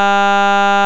impulsoFiltA.wav